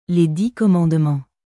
Les dix commandementsレ ディ コマォンドゥマォン